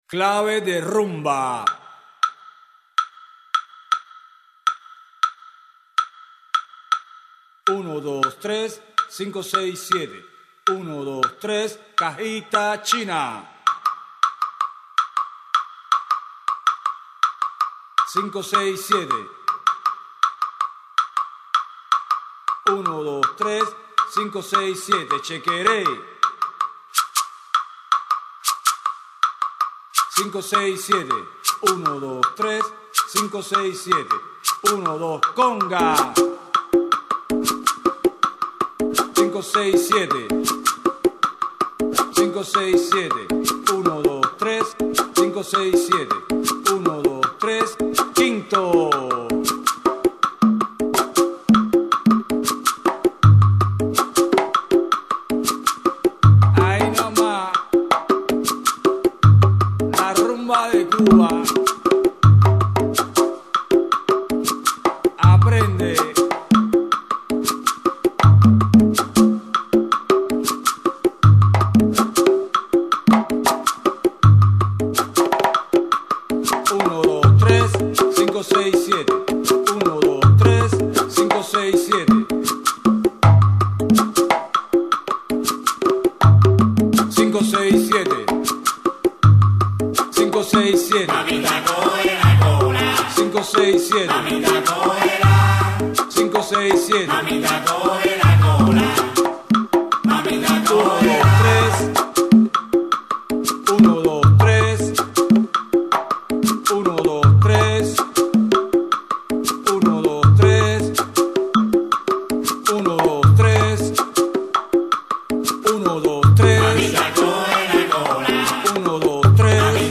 Rumba-Guaguanco-Instruments.mp3